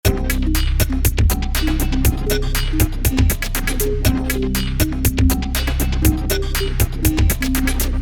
El efecto percibido es la señal seca del sonido ‘Pan flute’ en el centro de la mezcla con golpes ocasionales del ‘Pan flute’ en reverberación a la izquierda de la mezcla y delays ocasionales distorsionados de la ‘Pan flute’ a la derecha.
Aquí está el loop final con todos los efectos activados: